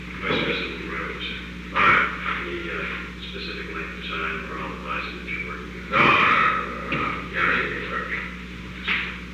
Secret White House Tapes
Conversation No. 908-23
Location: Oval Office